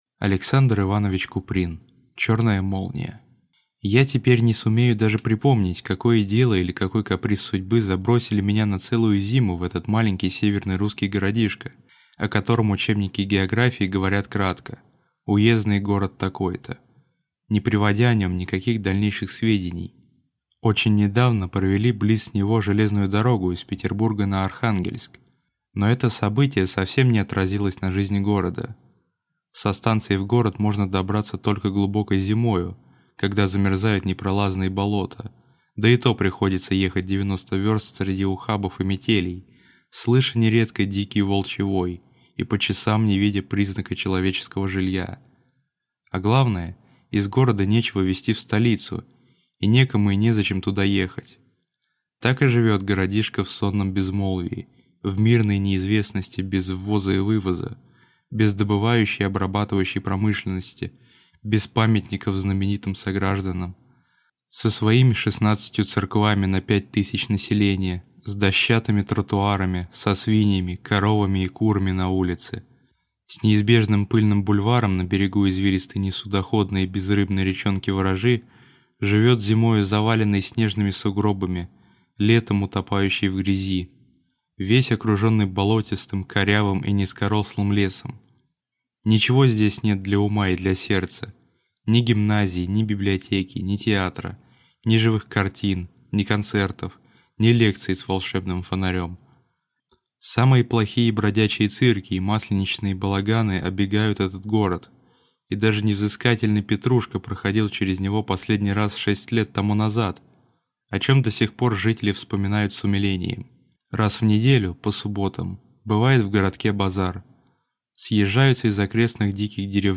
Аудиокнига Черная молния | Библиотека аудиокниг